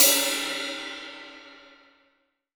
Index of /90_sSampleCDs/AKAI S-Series CD-ROM Sound Library VOL-3/16-17 CRASH